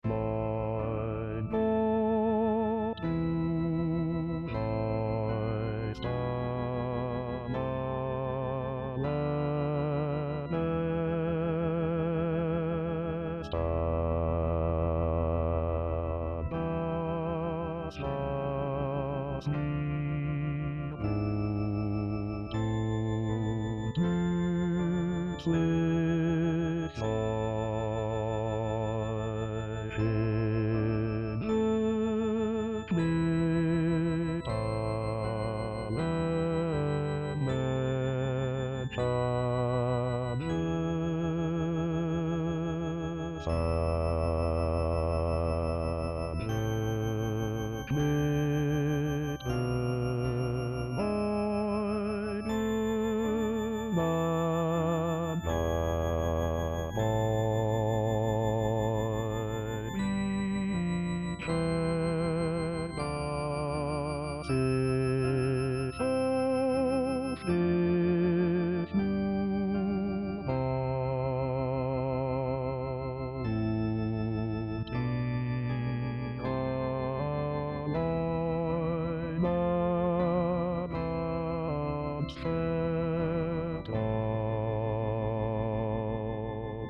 1er choeur:  Seigneur, tu es celui qui sait le mieux ce qui est bon et utile pour moi.
Voix MP3:   S   A   T